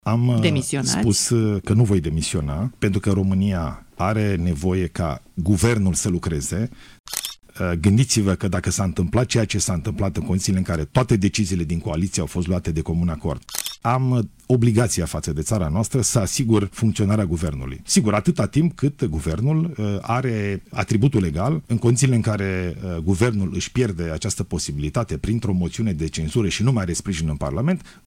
Nu voi demisiona din fruntea Guvernului, spune premierul Ilie Bolojan. Acesta a fost întrebat într-un interviu la postul public de radio dacă va renunța la funcție în cazul în care PSD va decide prin vot să-i retragă sprijinul.